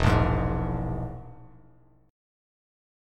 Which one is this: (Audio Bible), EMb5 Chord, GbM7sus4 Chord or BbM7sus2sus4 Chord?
EMb5 Chord